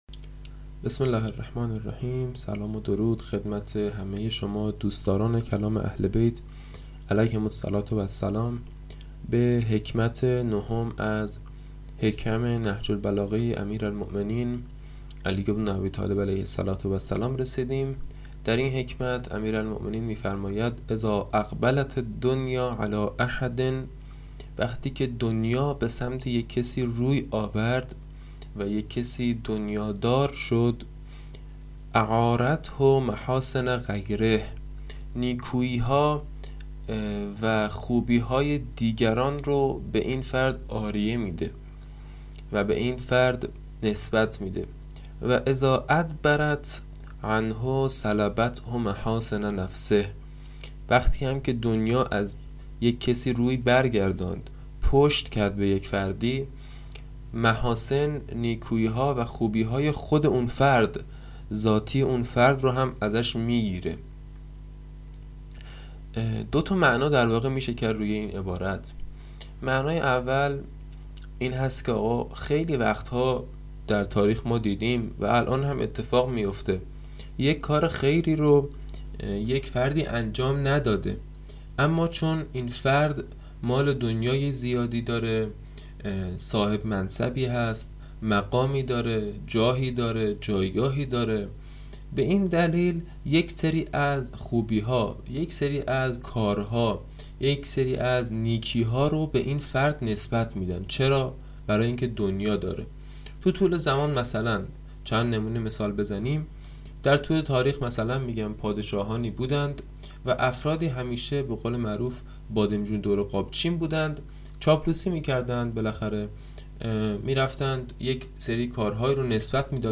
حدیث خوانی حکمت های نهج البلاغه